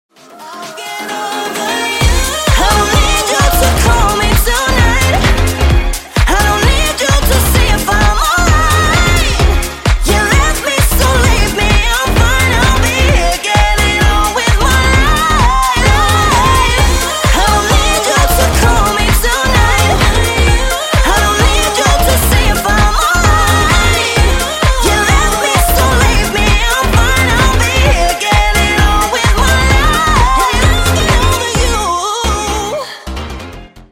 Клубные Рингтоны
Танцевальные Рингтоны